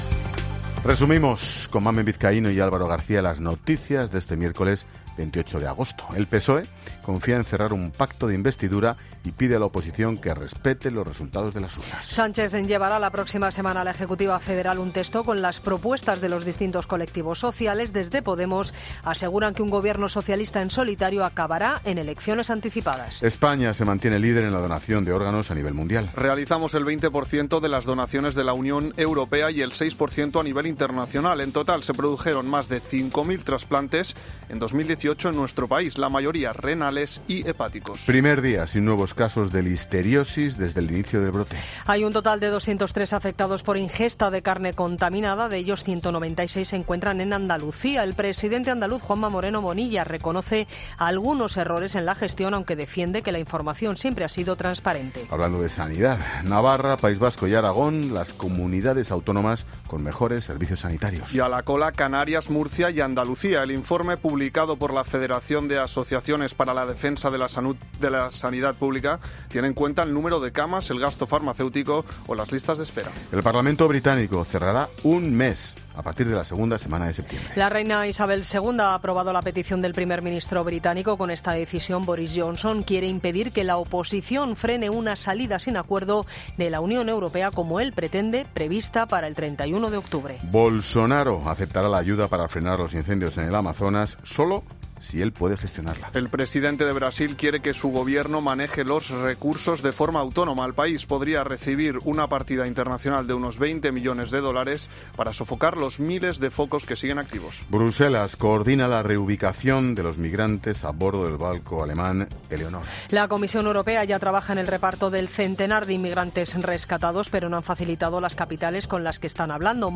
Boletín de noticias Cope del 28 de agosto a las 20.00 horas